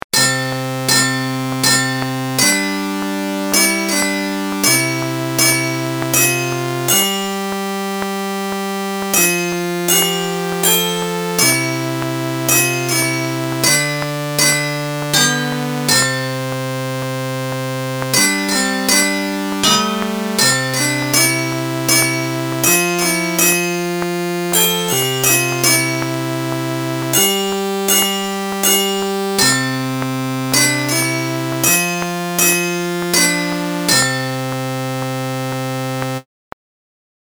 音MAD